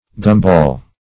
Search Result for " gumball" : The Collaborative International Dictionary of English v.0.48: gumball \gum"ball`\ (g[u^]m"b[add]l), n. A piece of chewing gum in the shape of a ball, usually covered with a colored glaze of sugar.